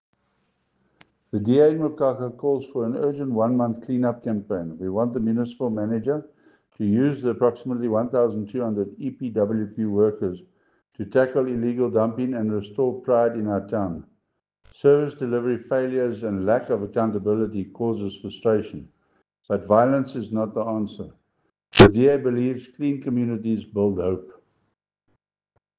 Afrikaans soundbites by Cllr Chris Dalton and